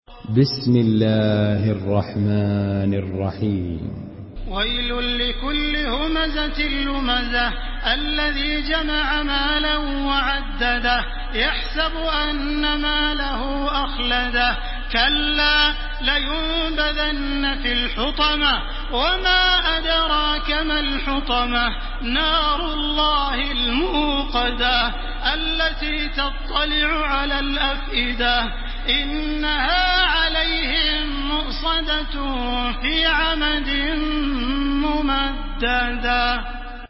تحميل سورة الهمزة بصوت تراويح الحرم المكي 1430
مرتل